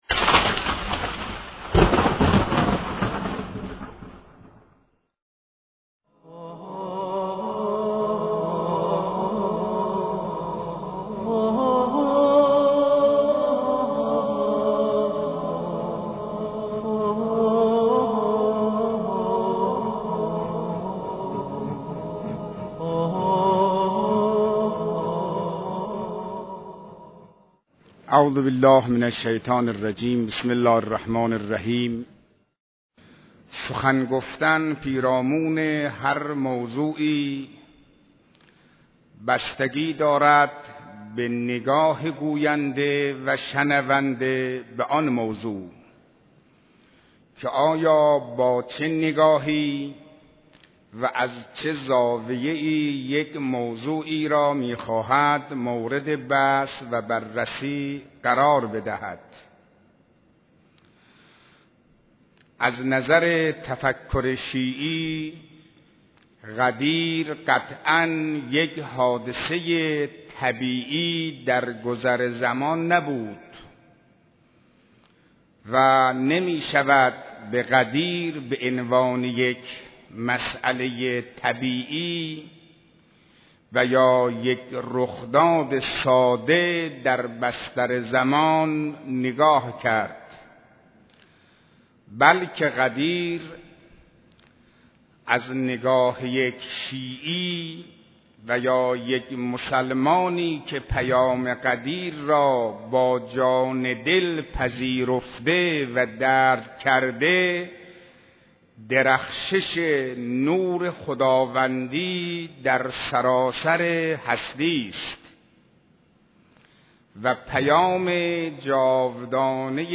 صوت سخنرانی آیت الله حسینی قزوینی